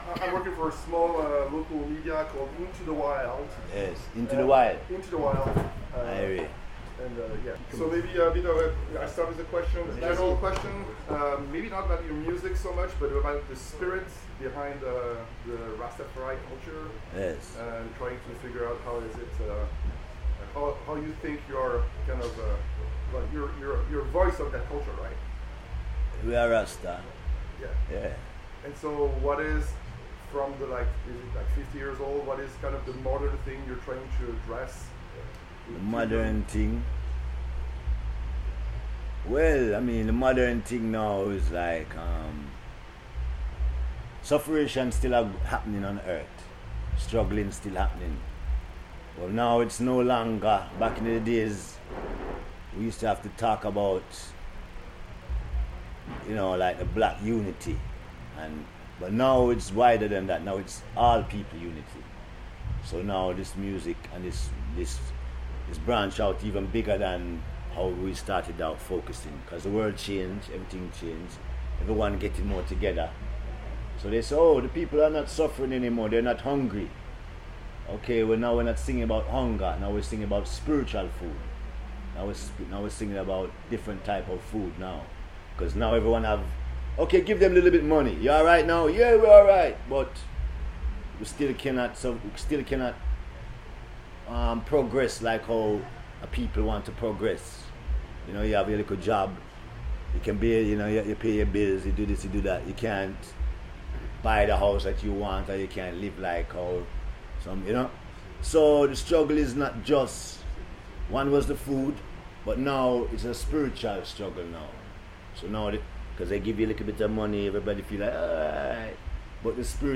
Interview Julian Marley
Les racines du reggae continuent de se propager avec Julian Marley en interview depuis le Paleo Festival de Nyon.